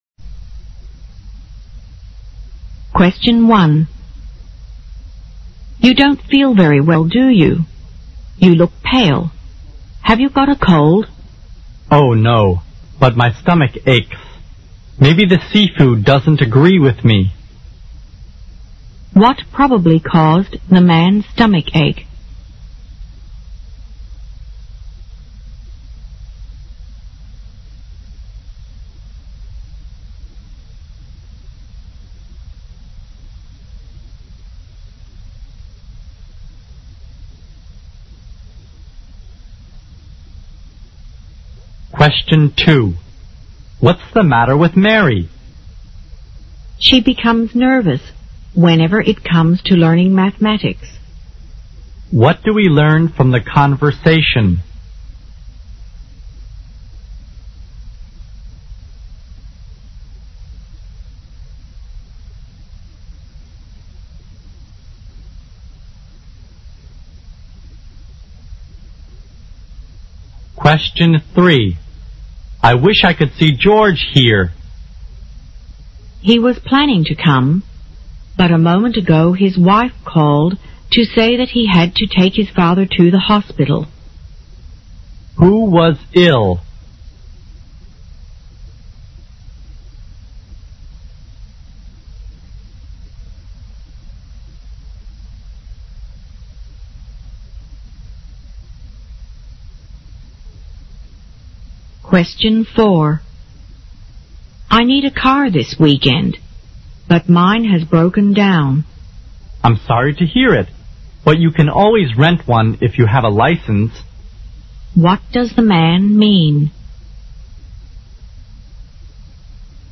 【CET12-24备战】四级听力 第六期 Short Conversation 听力文件下载—在线英语听力室